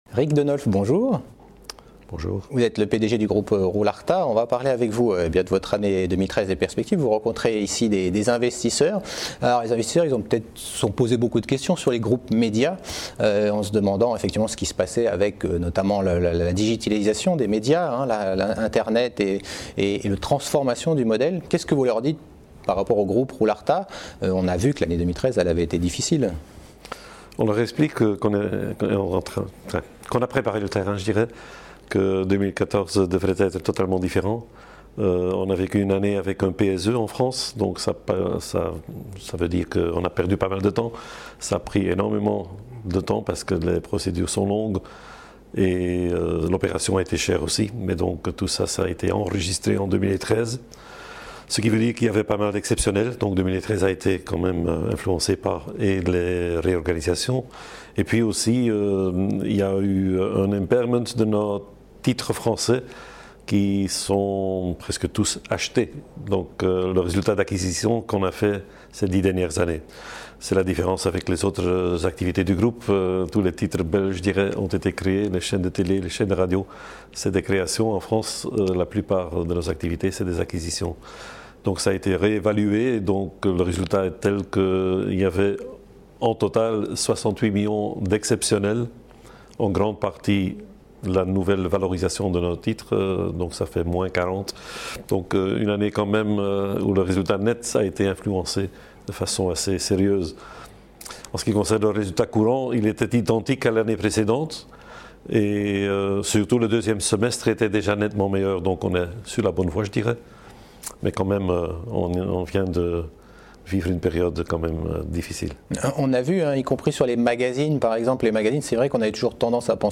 La Web Tv partenaire de l’European Small Cap Event organisé par CF&B Communicaiton qui s’est tenu à Paris le 7 et le 8 avril.